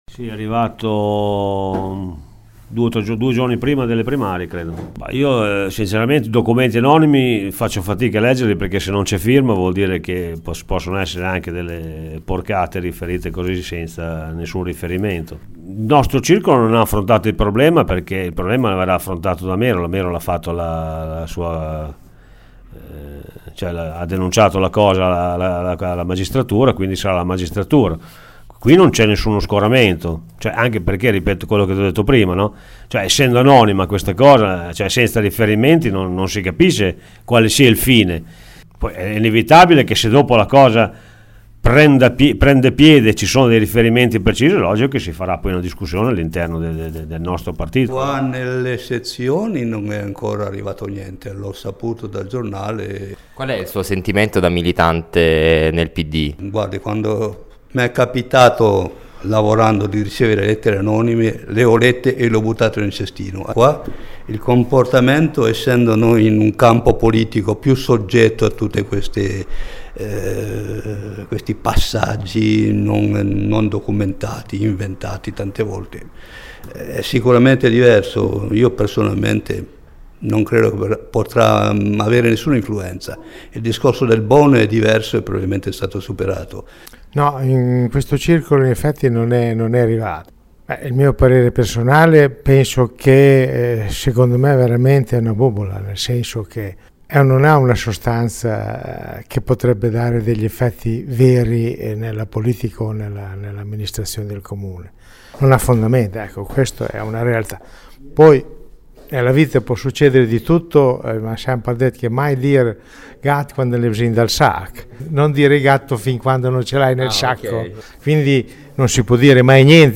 i rappresentanti o presidenti di circoli del PD (Borgo2, Borgo Panigale, Gramsci, Santa Viola):